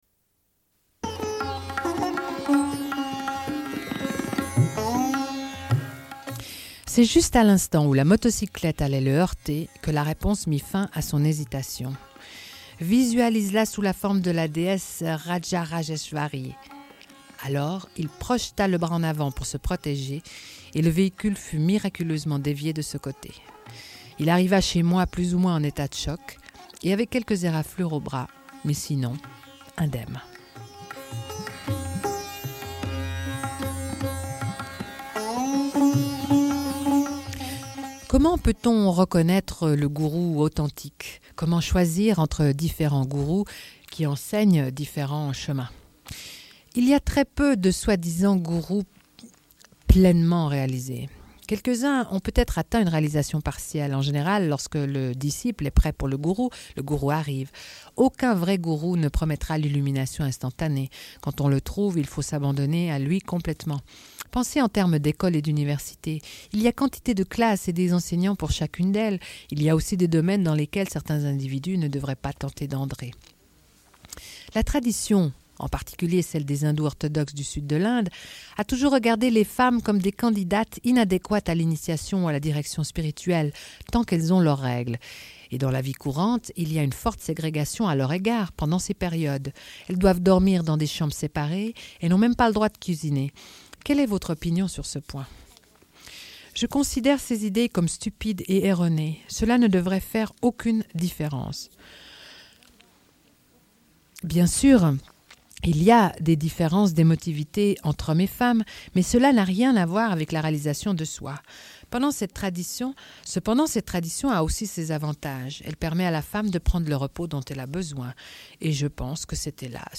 Une cassette audio, face A31:07